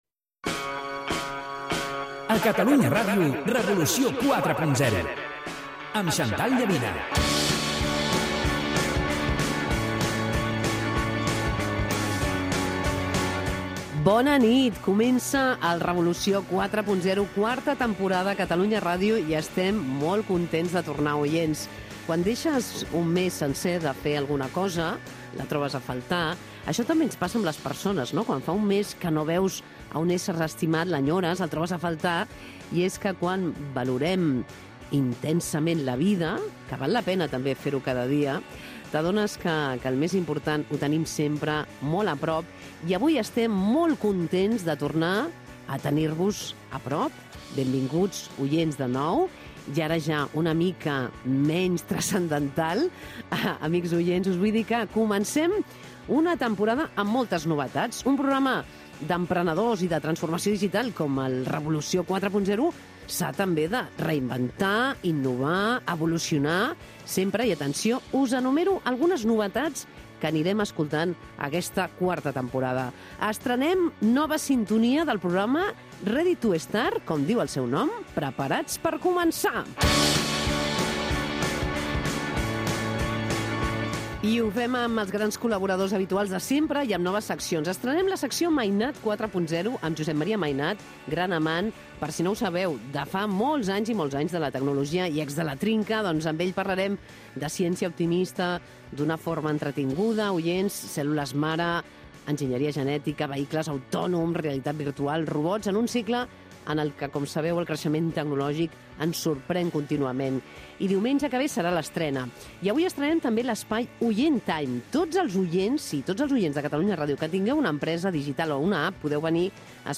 Careta del programa, presentació, novetats de la quarta temporada